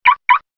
NGM_CarTone.ogg